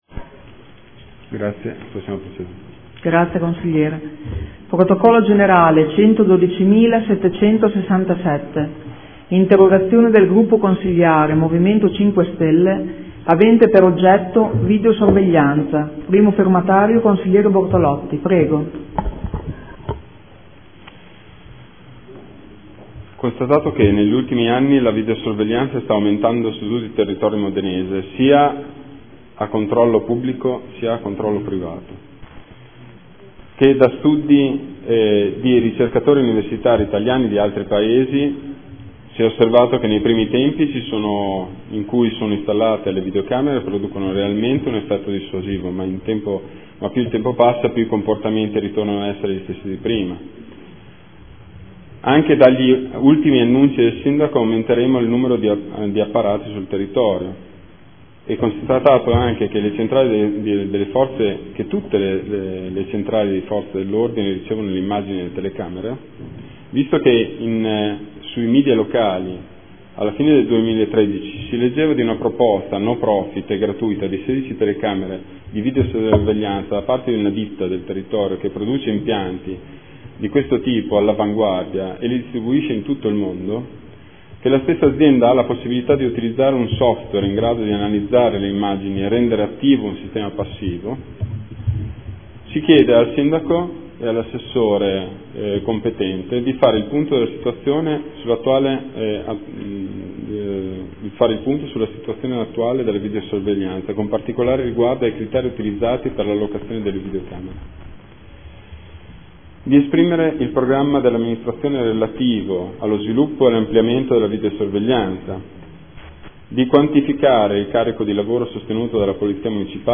Seduta del 9/10/2014 Interrogazione del gruppo consiliare Movimento 5 Stelle avente per oggetto: “Videosorveglianza”